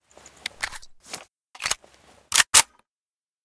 sr2m_reload_empty.ogg